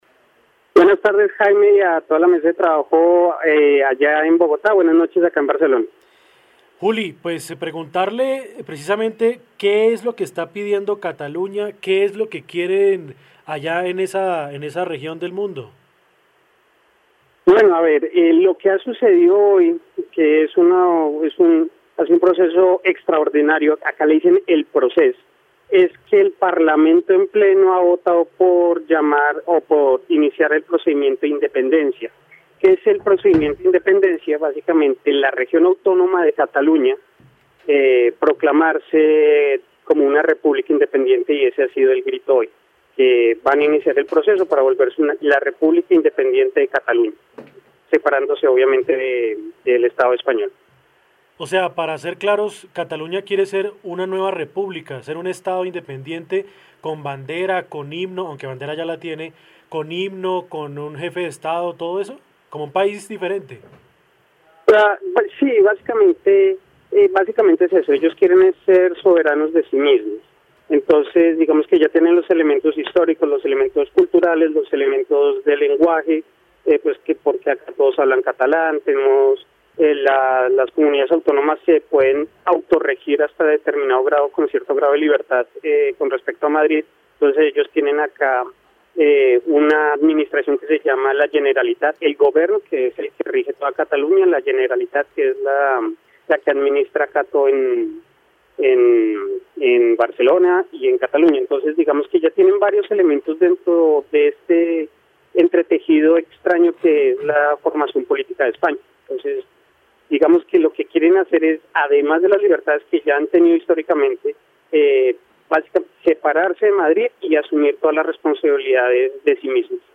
En diálogo con UNIMINUTO Radio estuvo